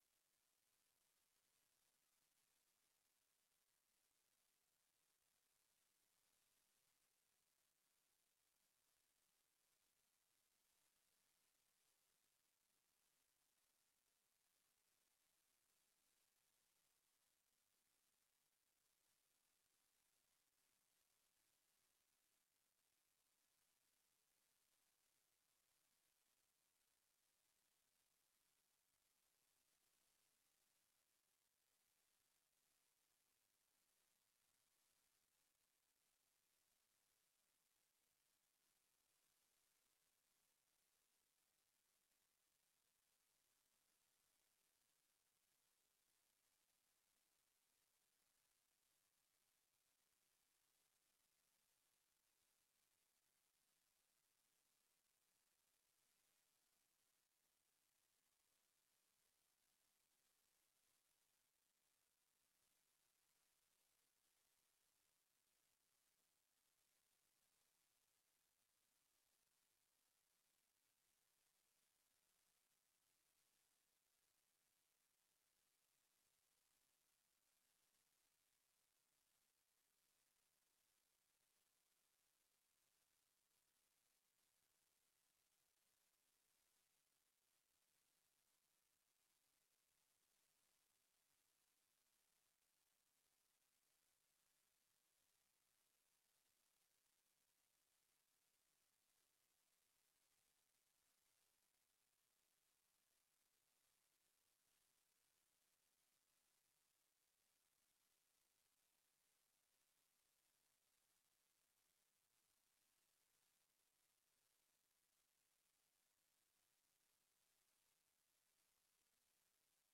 Raadsvergadering 16 december 2024 20:00:00, Gemeente Oude IJsselstreek
Locatie: DRU Industriepark - Conferentiezaal